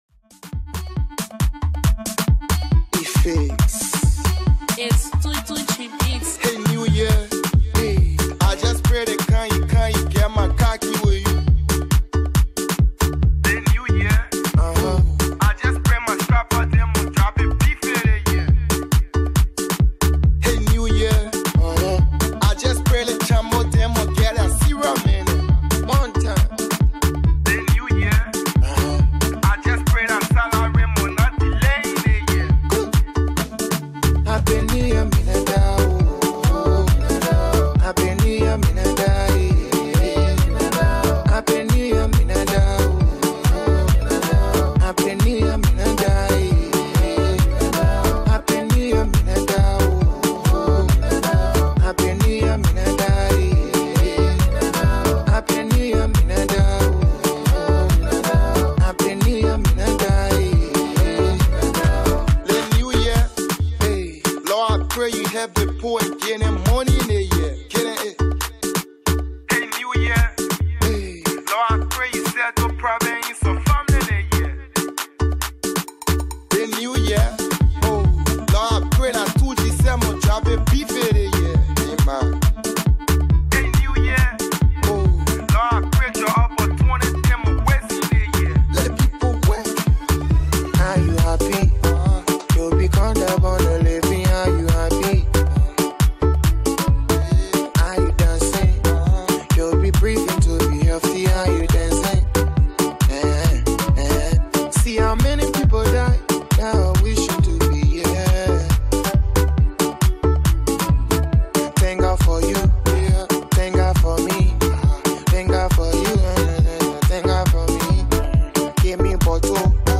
Hip-Co